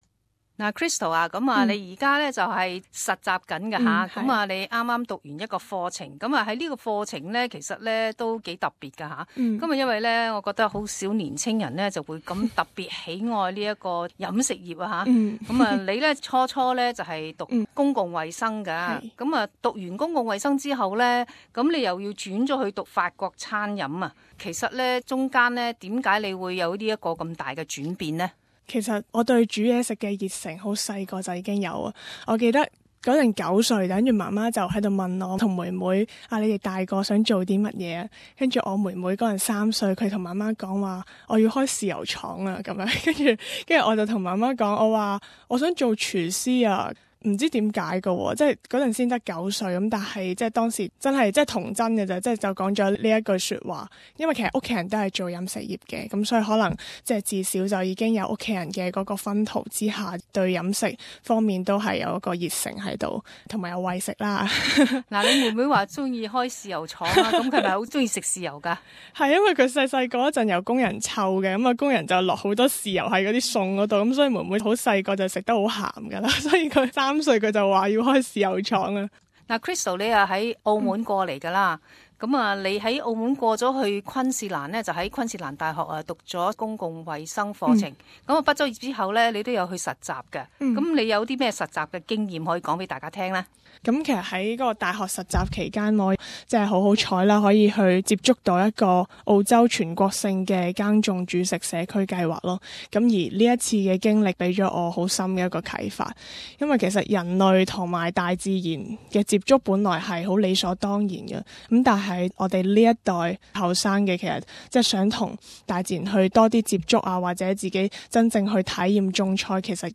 【社团专访】澳门年青人在澳洲创业的故事